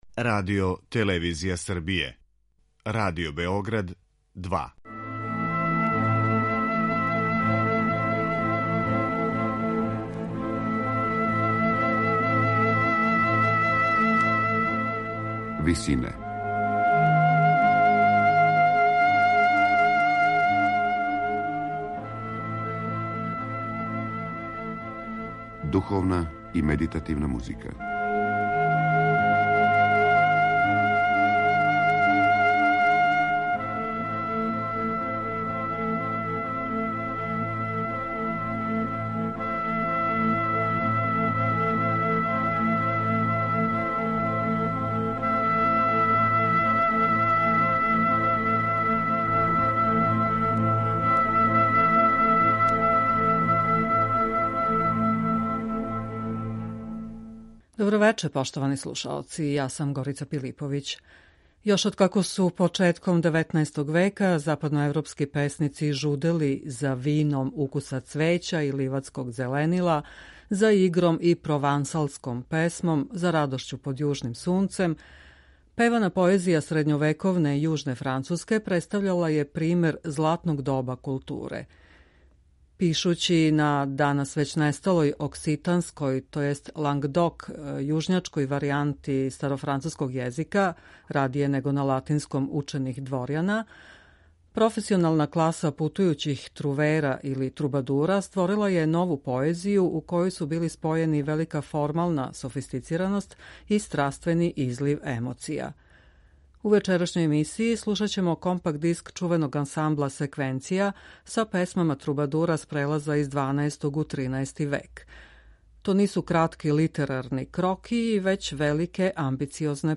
Песме француских трубадура
Мада има и а капела примера, песме су углавном праћене малим ансамблом гудачких инструмената и харфе. Њихове вокалне линије су изувијане, далеко су разрађеније од једноставних фолклорних напева чиме се такође указује на дубину и садржајност поетског текста.